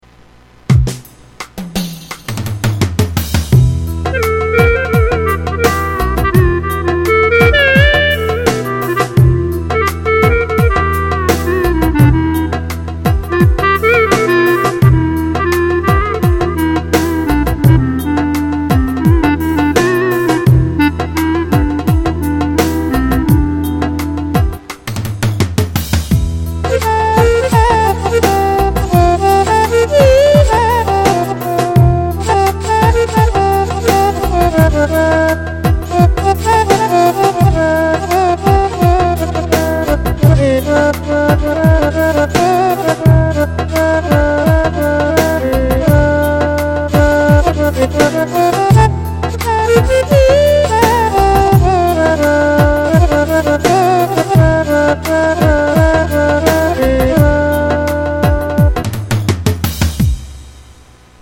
Pitchbendi abartılı kullandım gibi :) Yorumları bekliyorum.